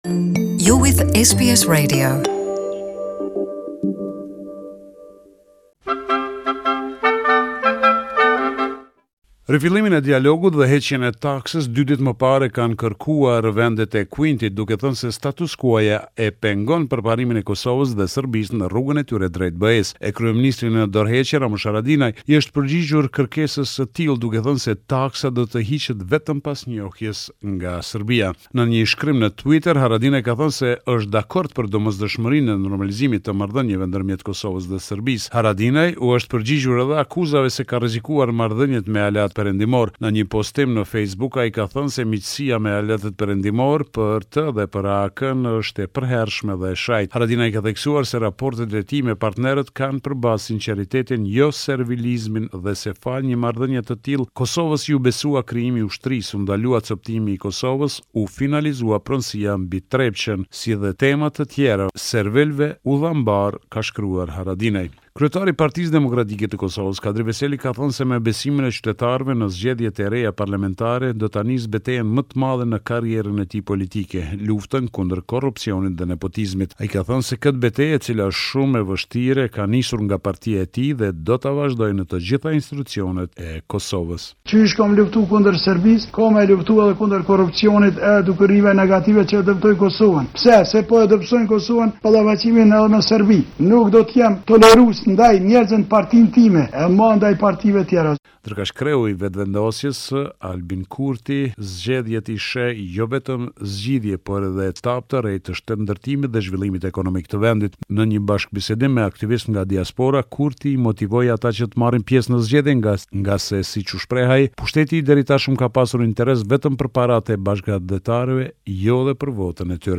This is a report summarising the latest developments in news and current affairs in Kosova.